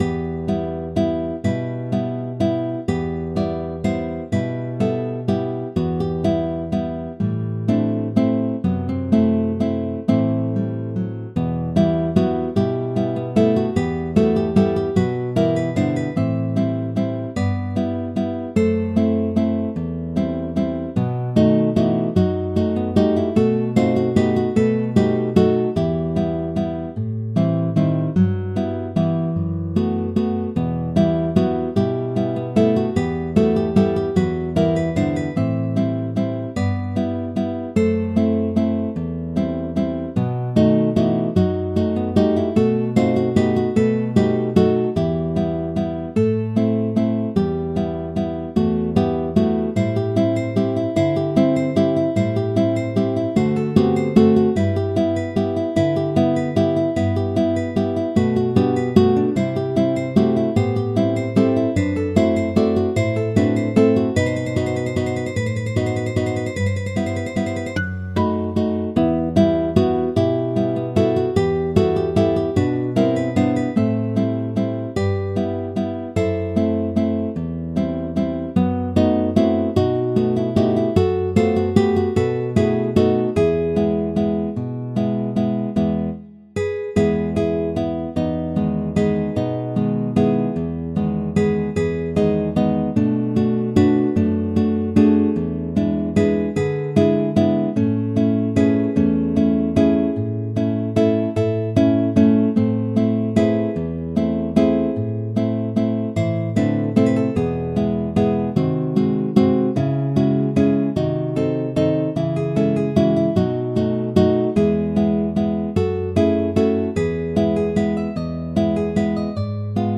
練習中の曲目